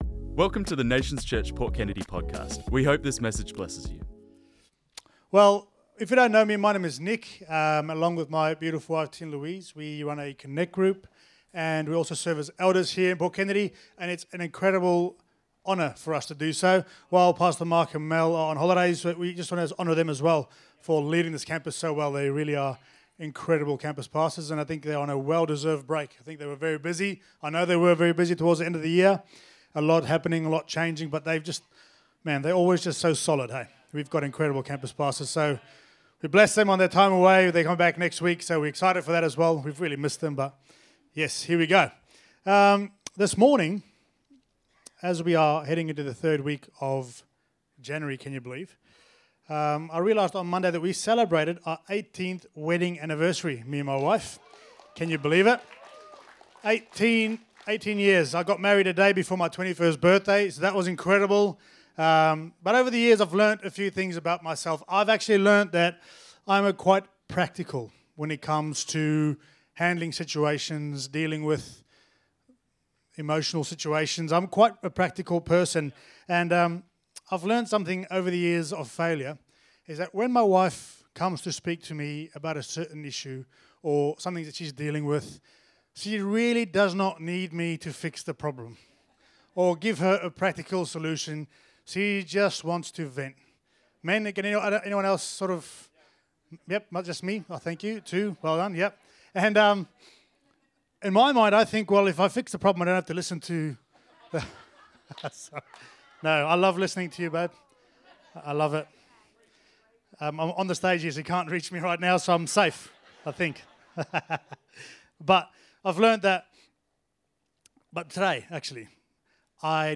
This message was preached on Sunday the 11th January 2026